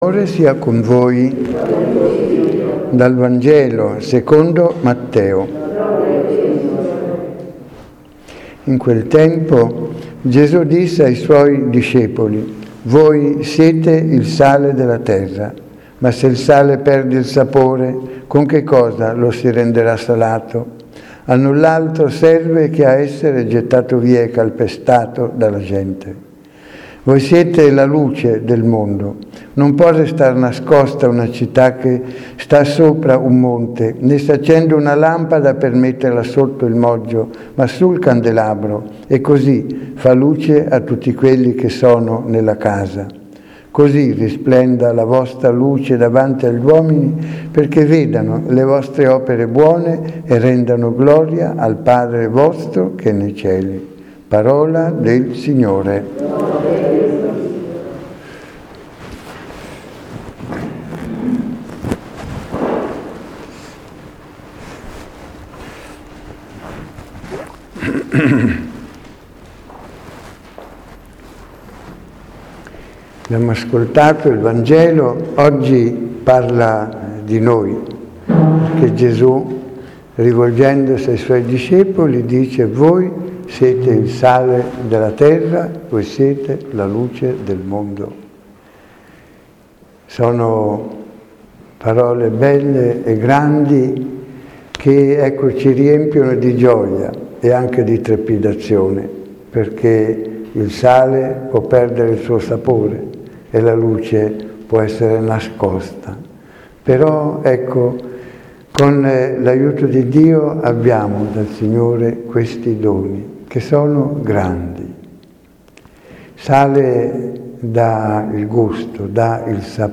L'omelia di Domenica 12 febbraio VI del T.O./A